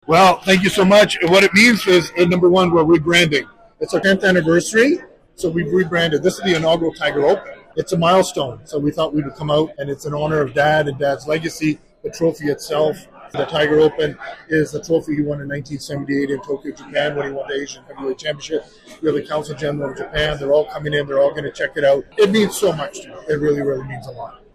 Just minutes before his routine drive to kickstart the golf tournament, Tiger shares with us how the annual event has been rebranded, as always, in memory of his father’s professional wrestling legacy.